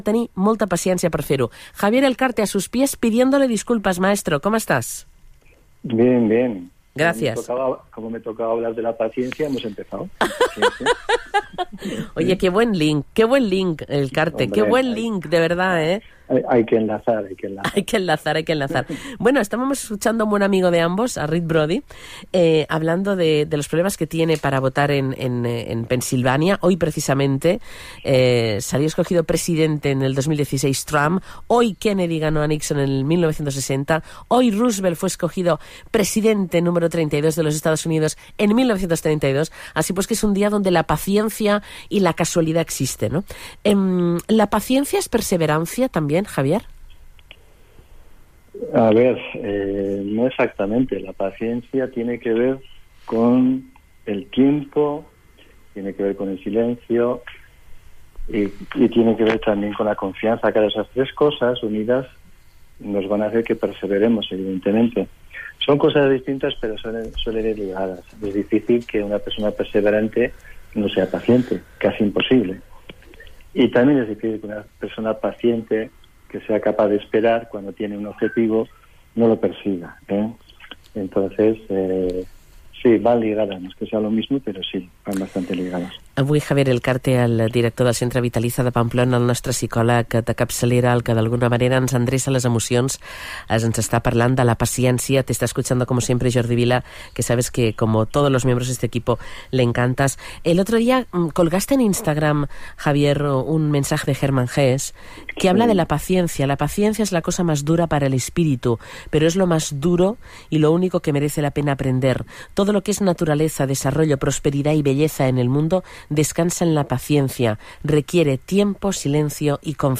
en el programa de radio De Boca a Orella de Radio Nacional de España-Radio4.